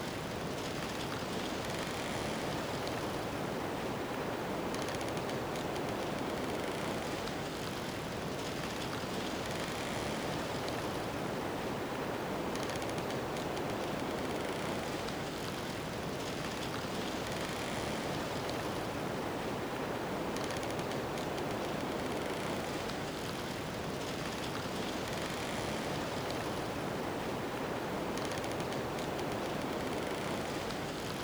Windy storm with trees rustling and bird croaking
Wind-Creaky-Bridge.ogg